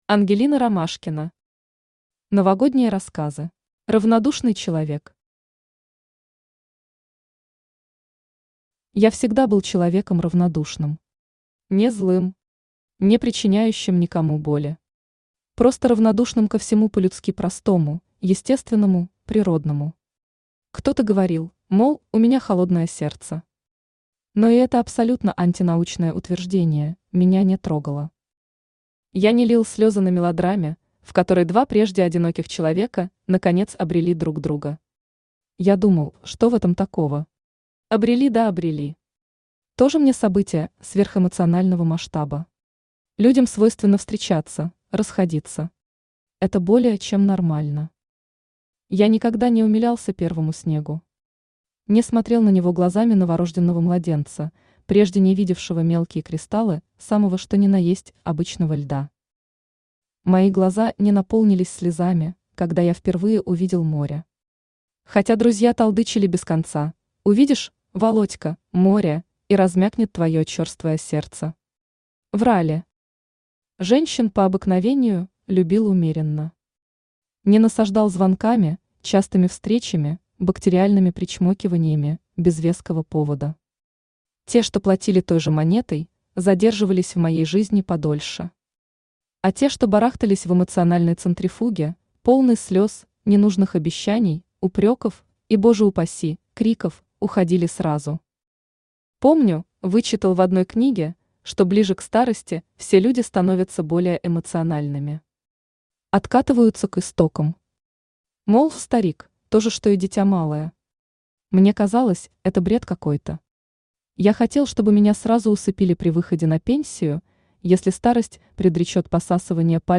Аудиокнига Новогодние рассказы | Библиотека аудиокниг
Aудиокнига Новогодние рассказы Автор Ангелина Ромашкина Читает аудиокнигу Авточтец ЛитРес.